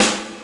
snare drum 3.wav